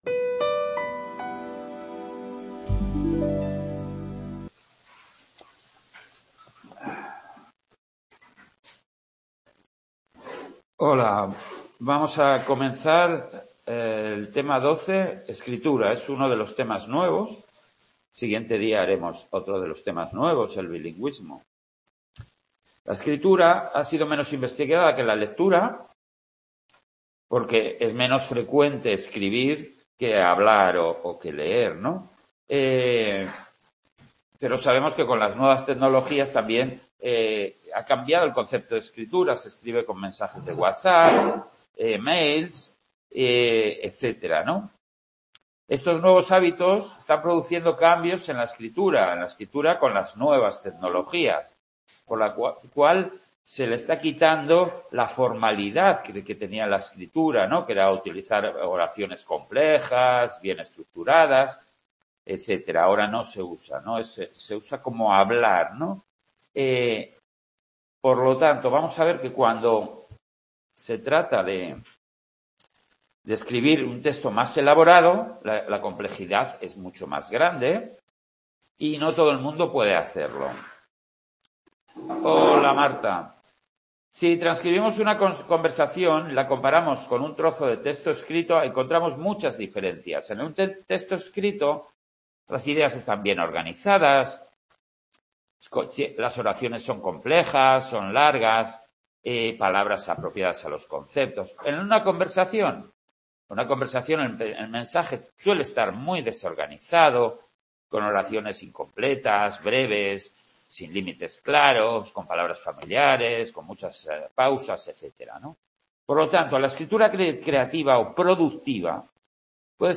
en el centro asociado de Sant Boi